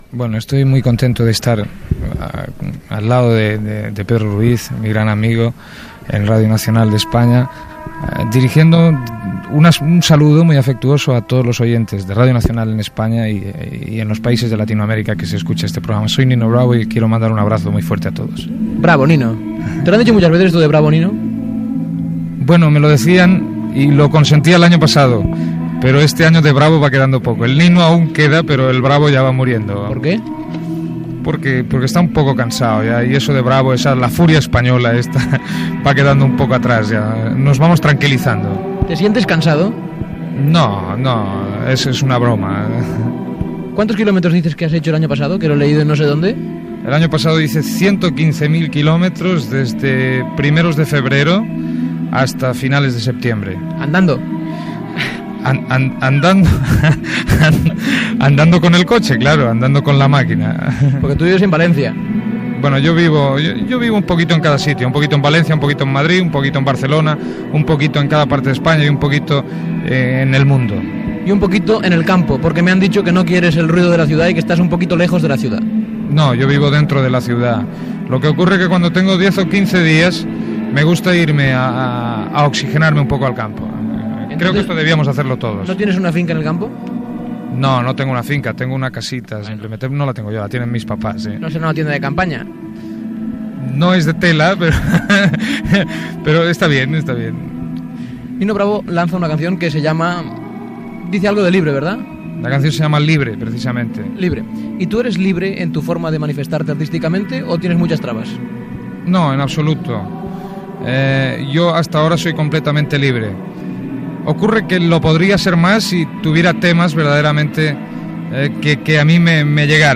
Entrevista al cantant Nino Bravo (Luis Manuel Ferri Llopis)
Entreteniment